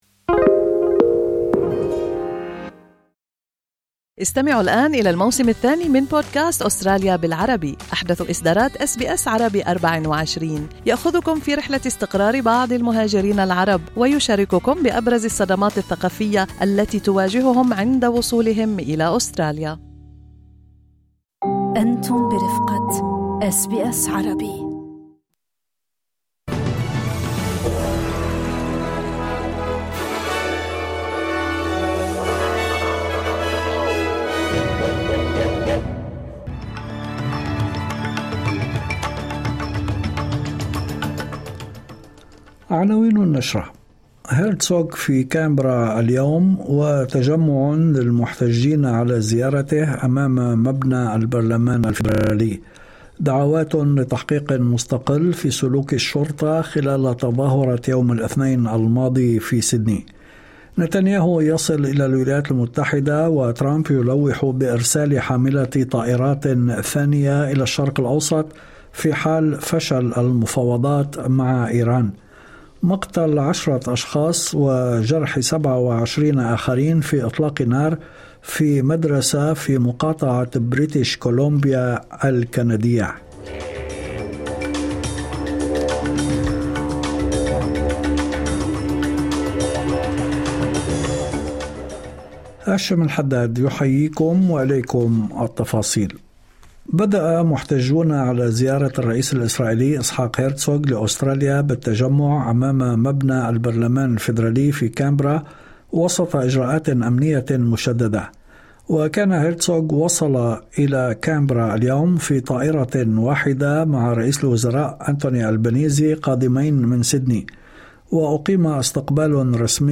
نشرة أخبار المساء 11/02/2026